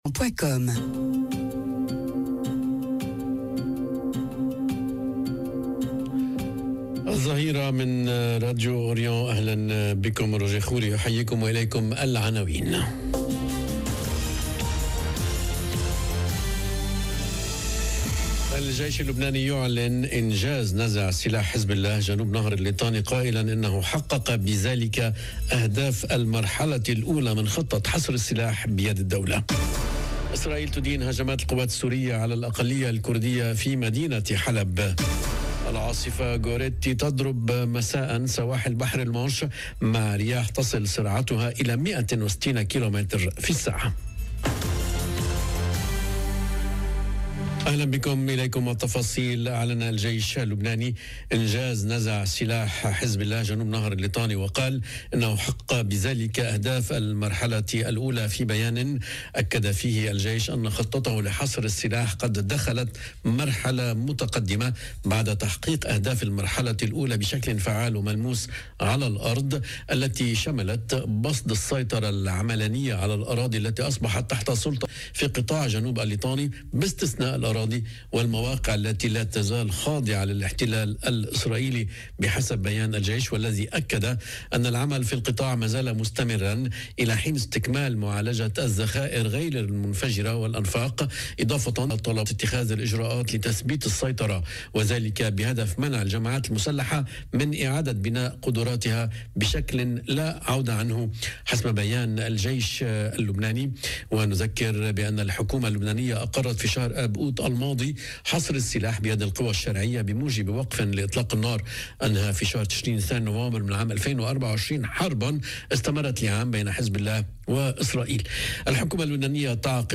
نشرة أخبار الظهيرة: الجيش اللبناني يعلن إنجاز نزع سلاح حزب الله جنوب نهر الليطاني، قائلا إنه حقق بذلك "أهداف المرحلة الأولى" من خطة حصر السلاح بيد الدولة....